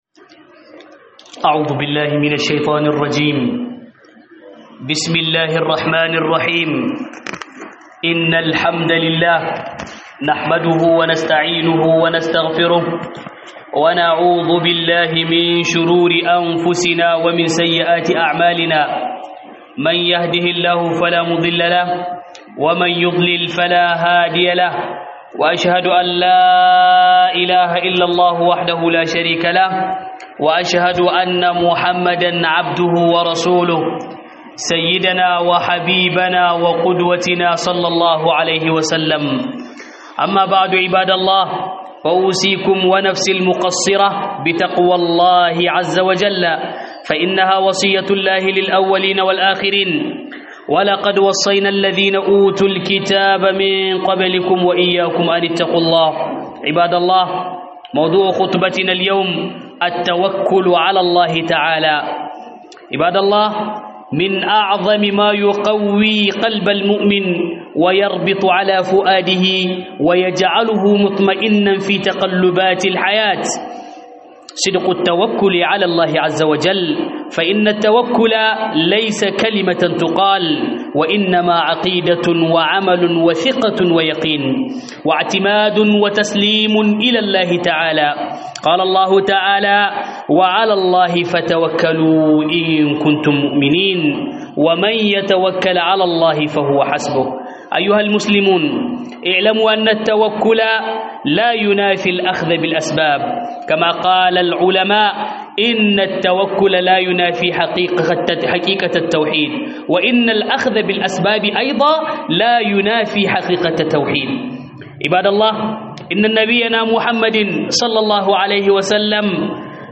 Huɗuba akan Mahimmancin Dogaro ga Allah - HUDUBA